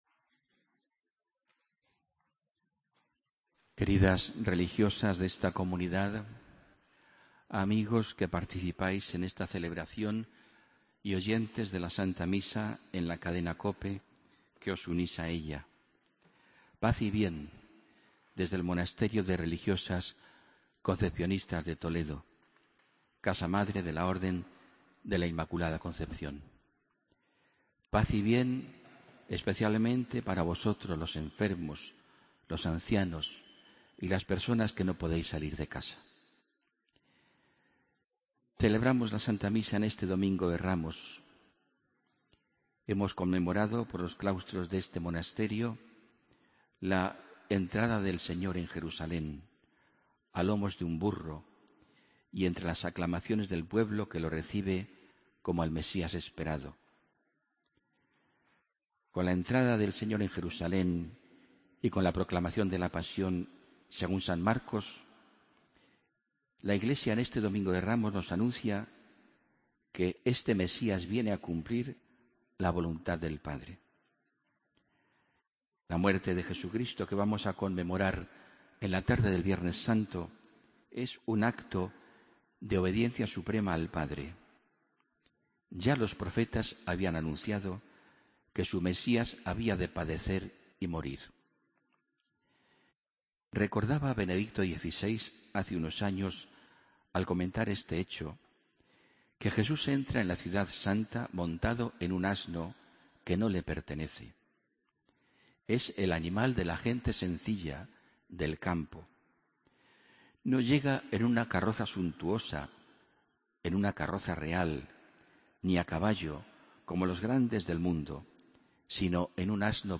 Homilía del 29 de marzo 2015